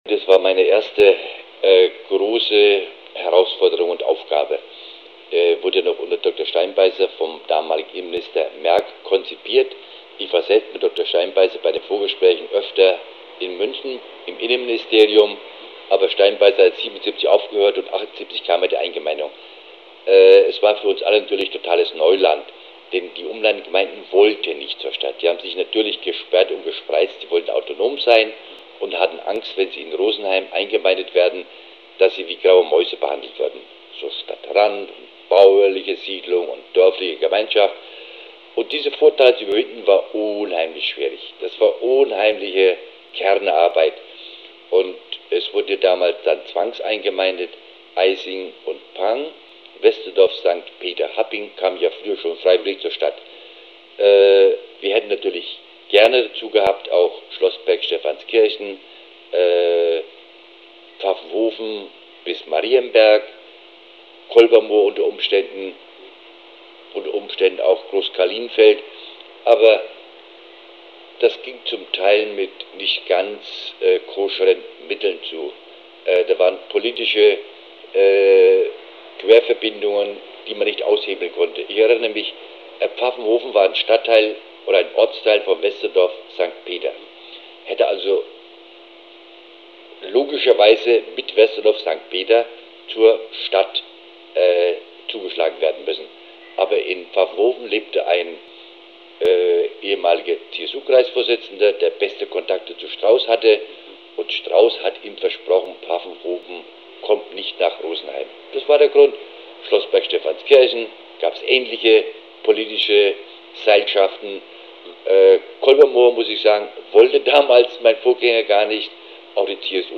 Zeitzeuge